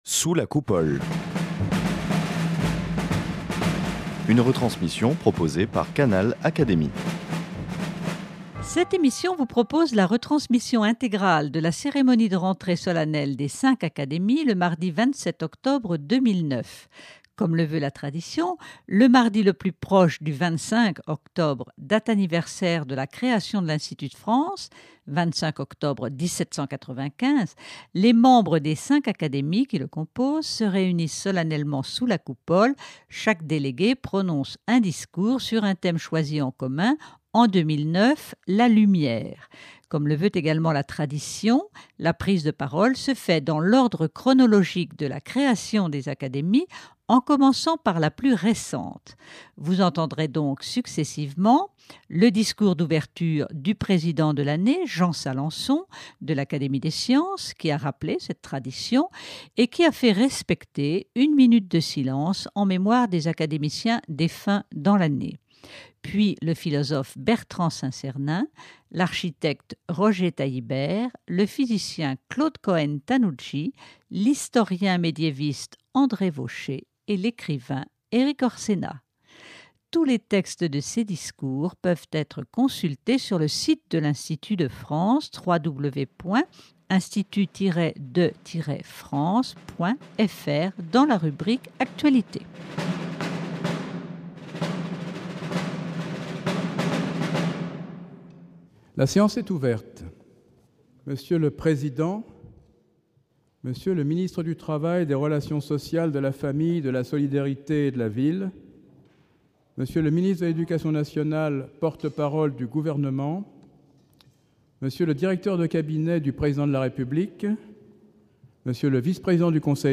Retransmission intégrale de la cérémonie de rentrée solennelle des cinq Académies le mardi 27 octobre 2009. Sur le thème de la lumière, cinq académiciens ont pris la parole : Bertrand Saint-Sernin, Roger Taillibert, Claude Cohen-Tannoudji, André Vauchez, Erik Orsenna.
Comme le veut la tradition, le mardi le plus proche du 25 octobre, date anniversaire de la création de l'Institut de France (25 octobre 1795), les membres des cinq Académies qui le composent se réunissent solennellement sous la Coupole. Chaque délégué prononce un discours sur un thème choisi en commun.